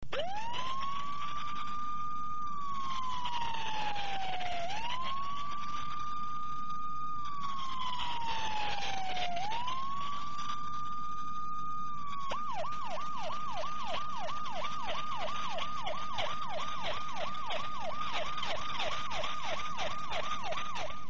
Fire siren 46999
• Category: Siren signals, alarms
• Quality: High